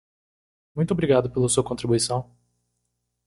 /kõ.tɾi.bu.iˈsɐ̃w̃/